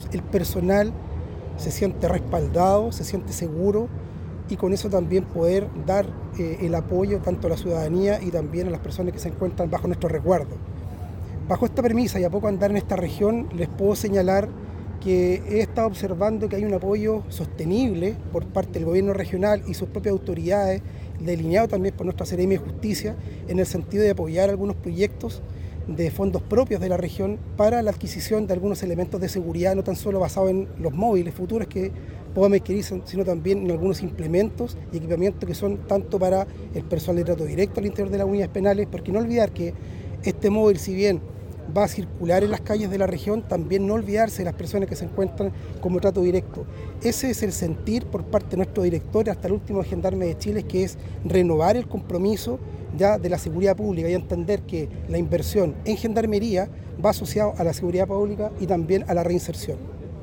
Por su parte, el director regional de Gendarmería, coronel Francisco Rojas, señaló sobre el sentir de la institución custodia relacionado a la entrega del carro blindado,